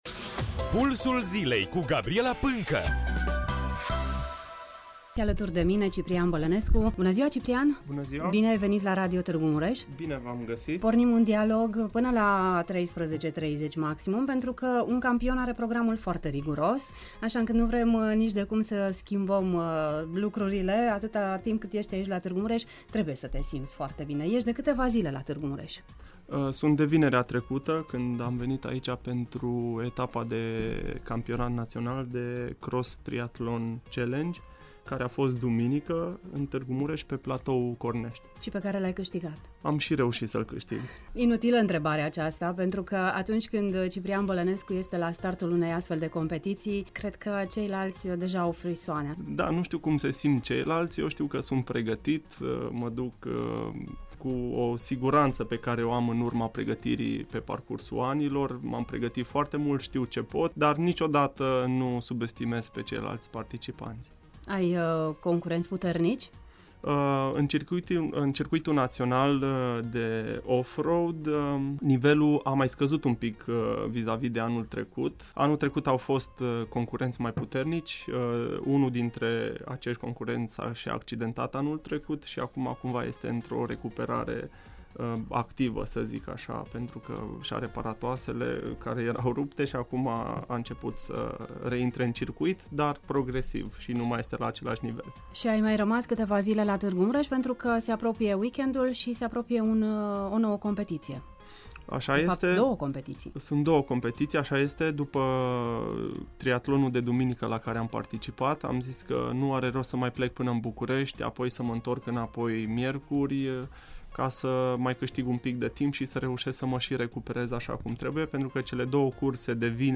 Între antrenamentele pentru competițiile din weekend, am povestit despre marea performanță în aquatlon, duatlon, triatlon, despre locul său de muncă – cel mai frumos din lume, despre proiectul JO de la Tokyo 2020, proiect cu care își dorește să deschidă un nou capitol în cariera sa sportivă. Nu am lăsat deoparte poveștile despre proiectele sale împreună cu micii sportivi sau despre cât de mult iubește România. Ascultati un interviu sincer, contracronometru